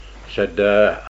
the-phonology-of-rhondda-valleys-english.pdf